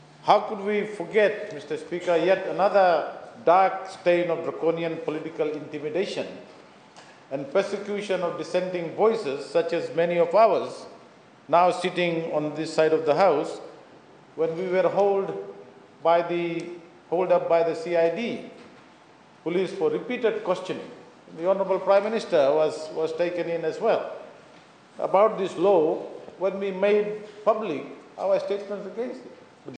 Professor Prasad, while speaking in support of the repeal of the bill, says the previous government railroaded the amendment under Standing Order 51, with no consultation whatsoever with the Act’s primary stakeholders, who are our iTaukei customary land owners.
The bill to repeal the Act was debated and passed in Parliament.